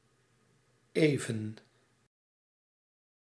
Ääntäminen
IPA: /ˈeː.və(n)/ IPA: /e.vǝⁿ/